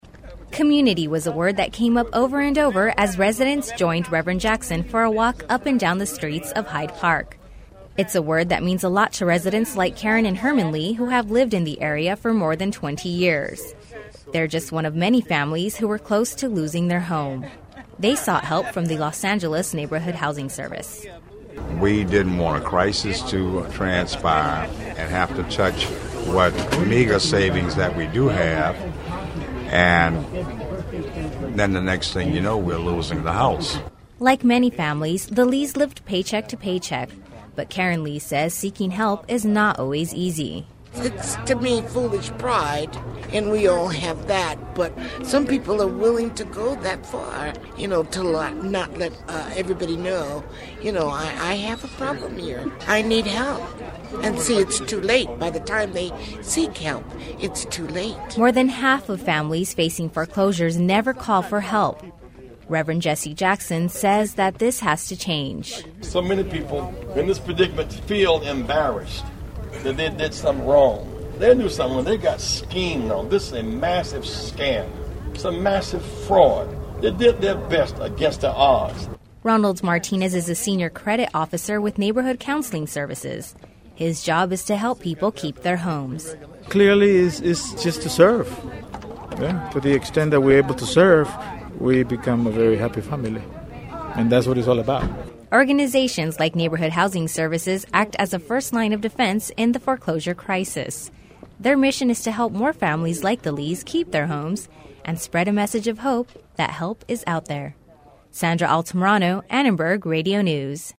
Reverend Jesse Jackson spoke out about the foreclosure crisis in the Hyde Park area of South Los Angeles. With more than 100 families losing their homes in Los Angeles every week, it is no wonder that something has to be done.